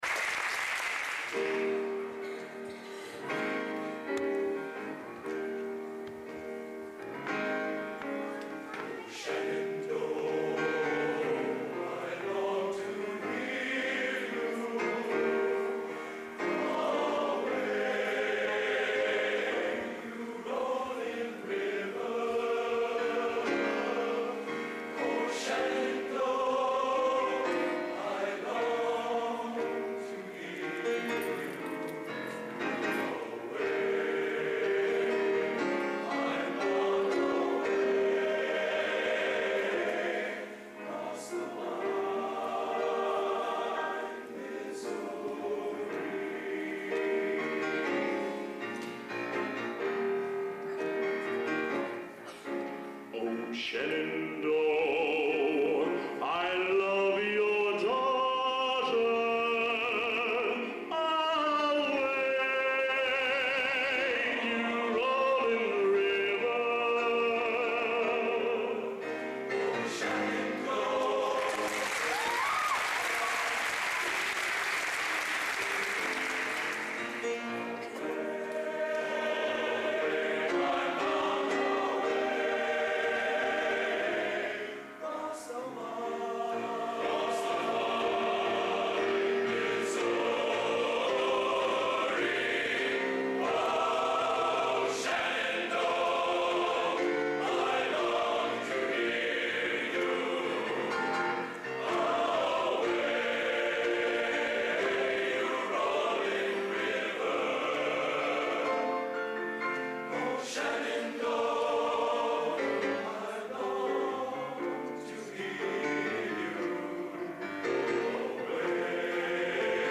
Location: Purdue Memorial Union, West Lafayette, Indiana
Genre: Traditional | Type: